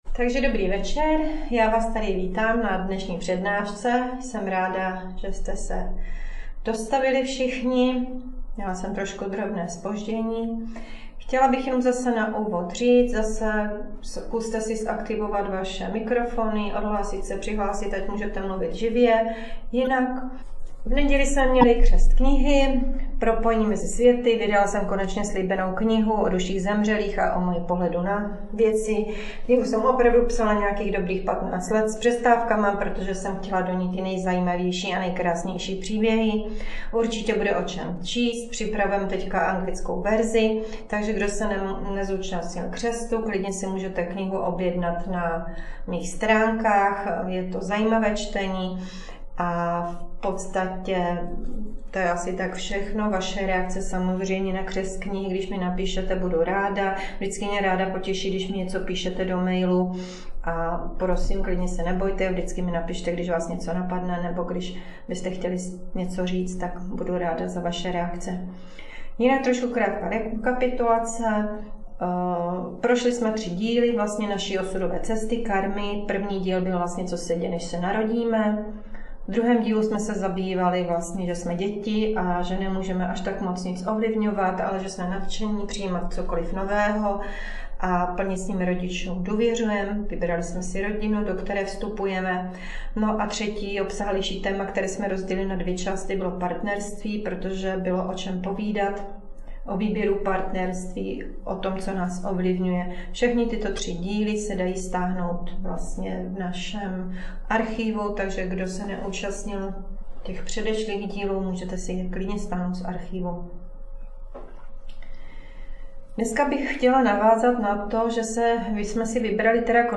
Přednáška Karma 4/8 - Rodičovství a vazby k dětem a rodině